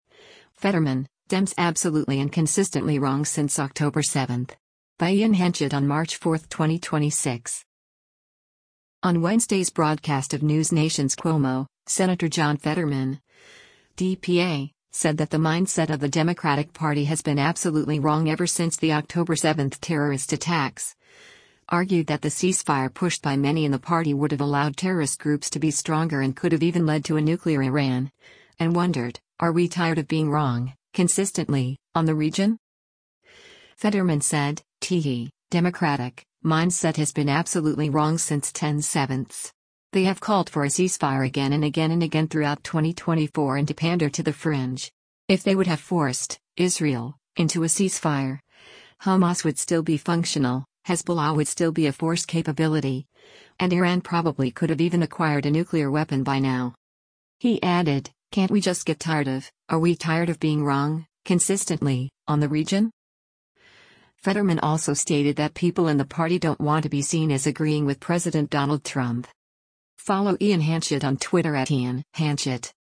On Wednesday’s broadcast of NewsNation’s “Cuomo,” Sen. John Fetterman (D-PA) said that the mindset of the Democratic Party “has been absolutely wrong” ever since the October 7 terrorist attacks, argued that the ceasefire pushed by many in the party would have allowed terrorist groups to be stronger and could have even led to a nuclear Iran, and wondered, “are we tired of being wrong, consistently, on the region?”